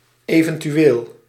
Ääntäminen
US : IPA : ['pɒ.sɪ.bli]